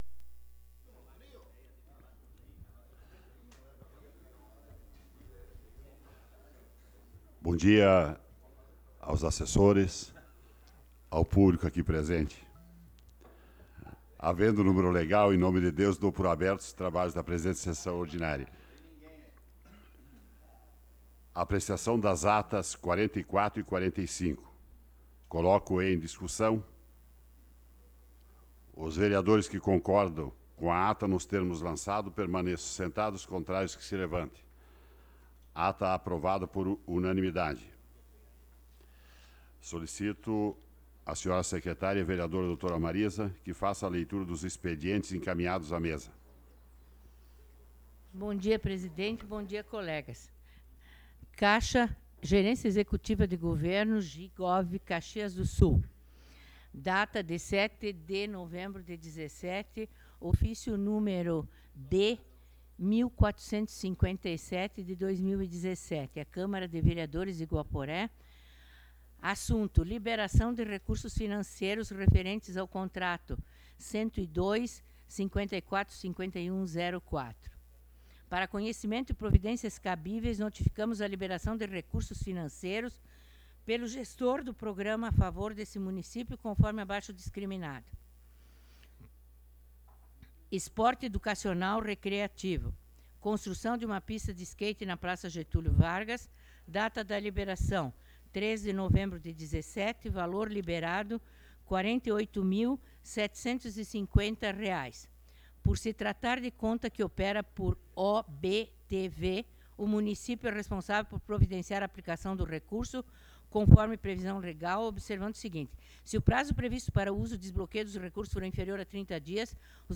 Sessão Ordinária do dia 07 de Dezembro de 2017